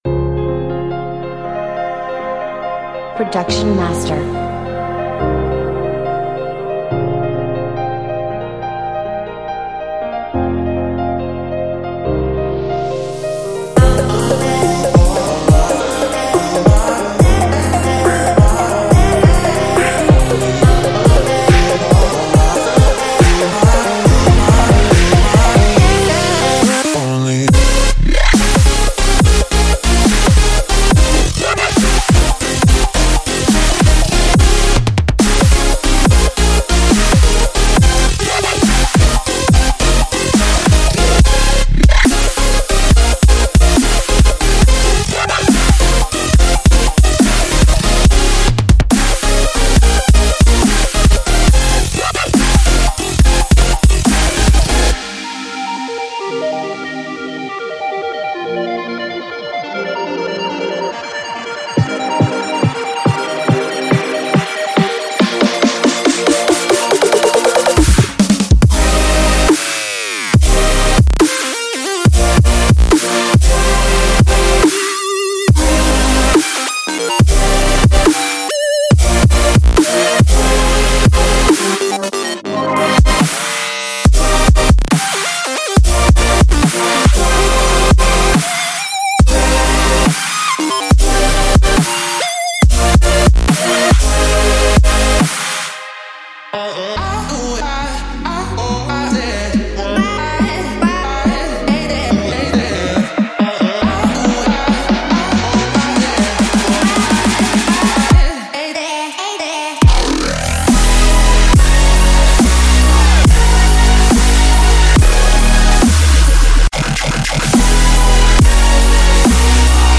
2026-01-01 Melodic Dubstep 232 推广